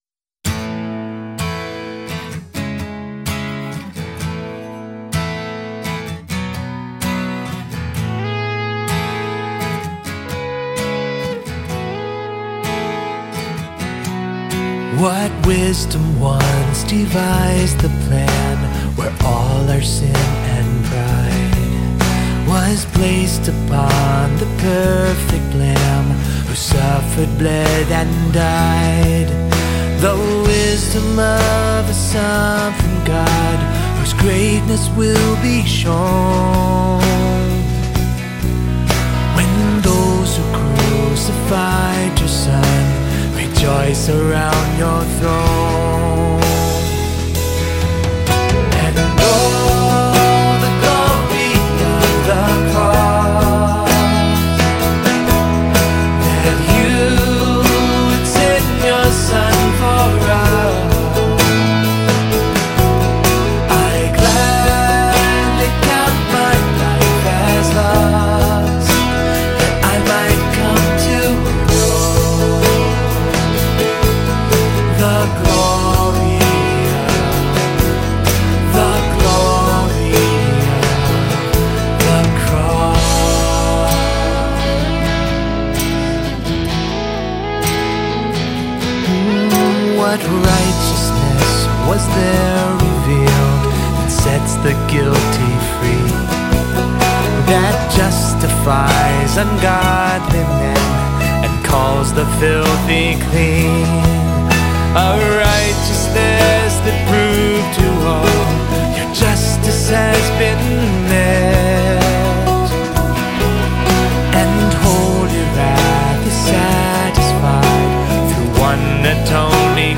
medium tempo, guitar driven song